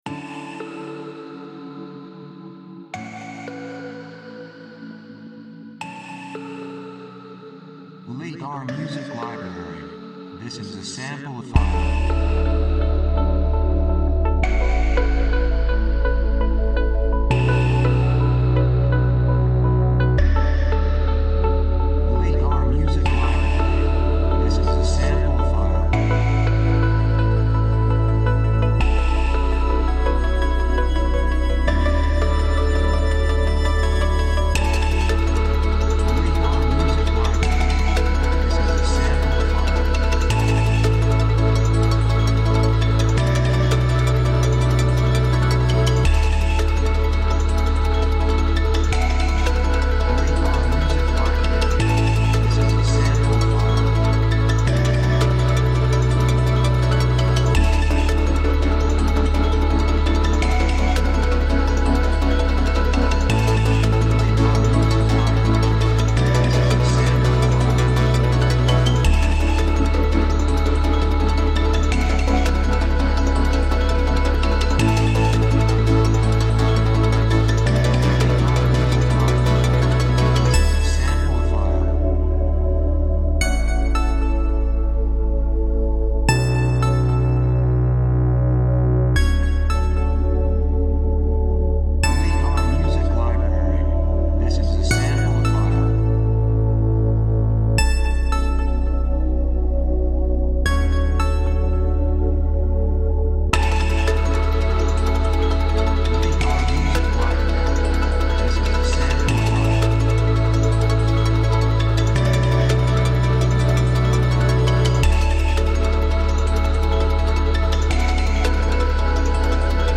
2:59 167 プロモ, エレクトロニック
雰囲気エネルギッシュ, 幸せ, せわしない, 高揚感, 決意, 夢のような, 喜び
楽器シンセサイザー
サブジャンルドラムンベース
テンポとても速い